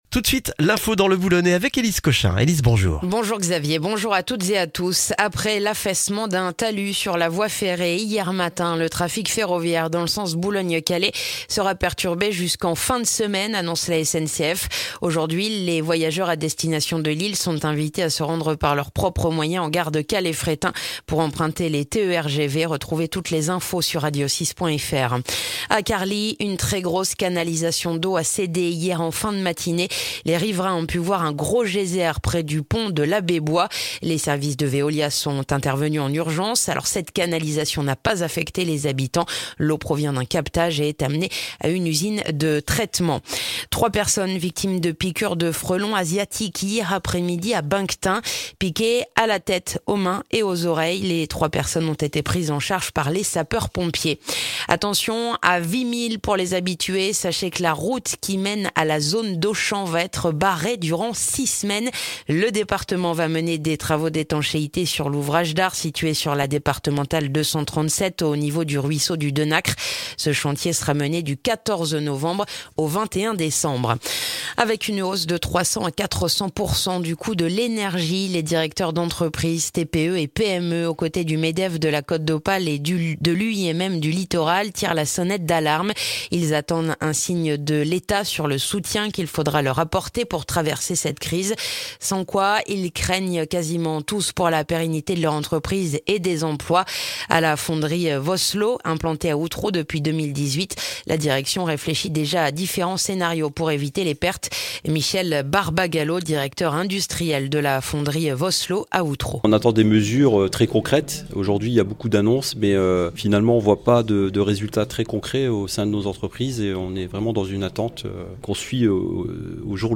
Le journal du mercredi 9 novembre dans le boulonnais